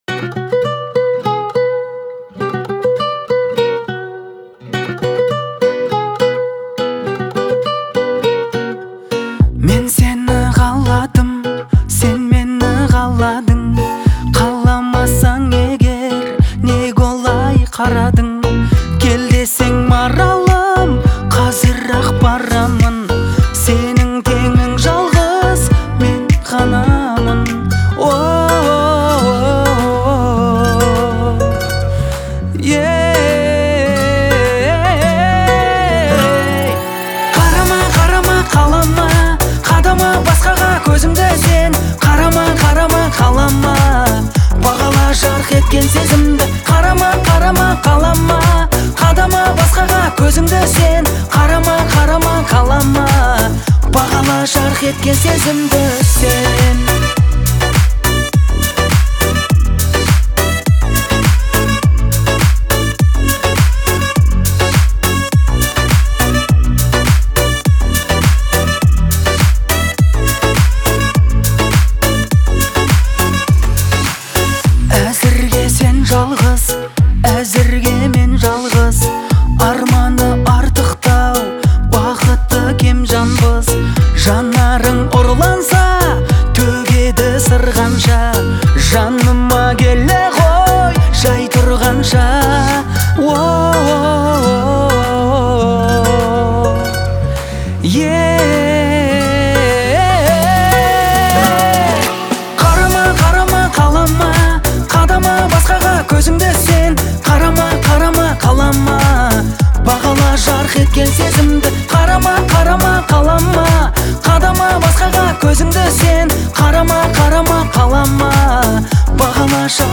яркая и эмоциональная песня
его голос наполнен искренностью и страстью.